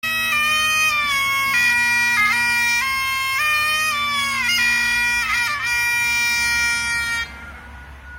Cornemuse
cornemuse.mp3